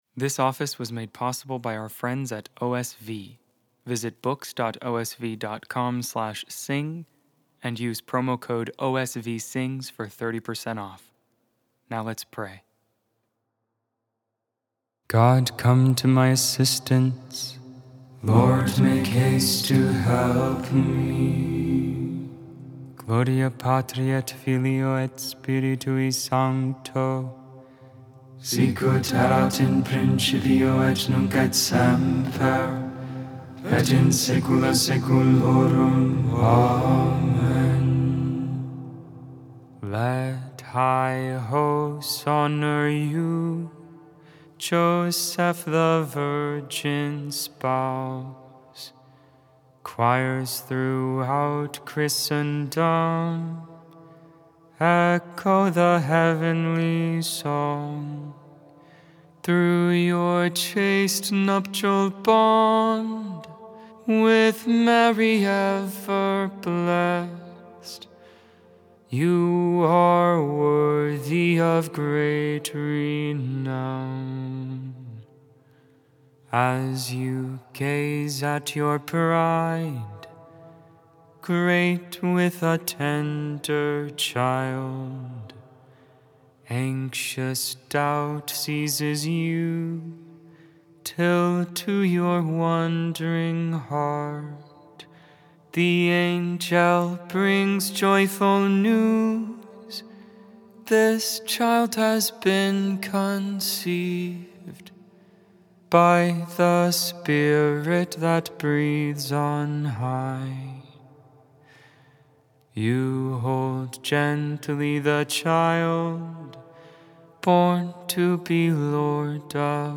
3.19.25 Vespers II, Wednesday Evening Prayer of the Liturgy of the Hours